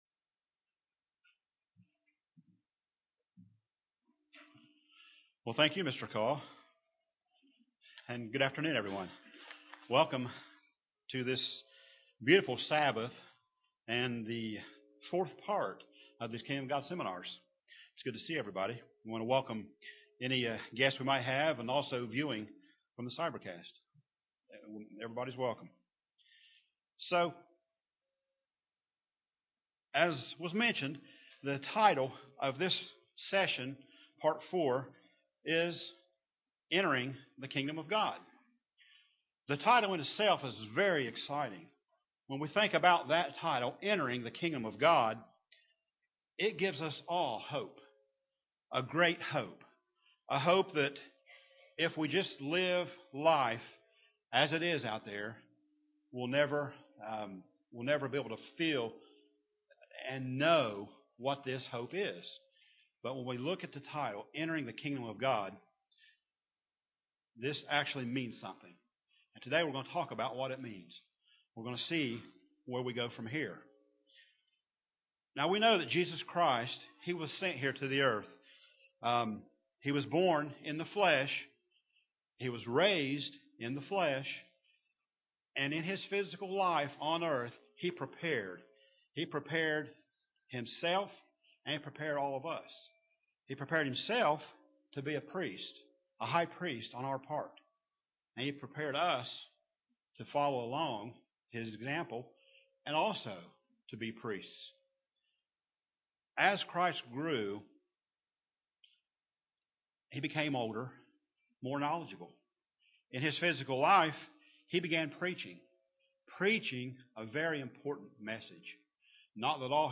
Learn more about what God expects of us in order to enter His Kingdom in this Kingdom of God seminar.
UCG Sermon Studying the bible?